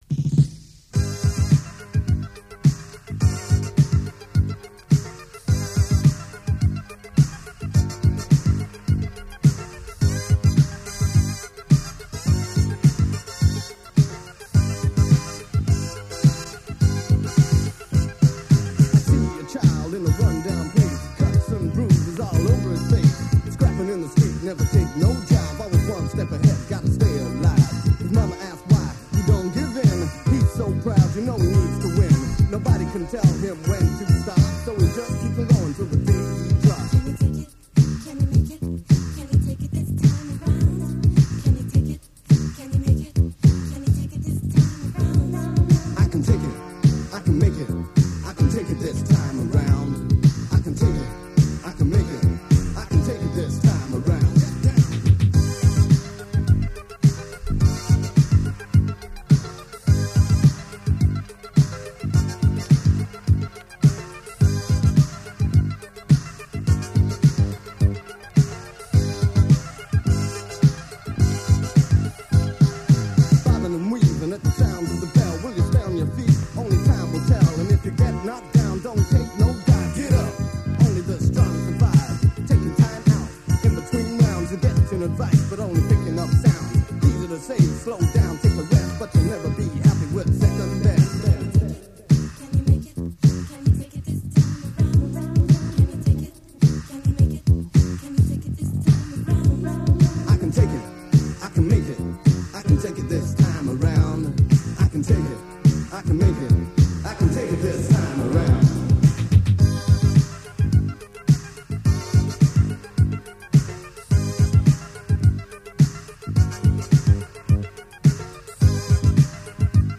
• radio mix
• Bass and Co-author